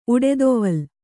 ♪ uḍedoval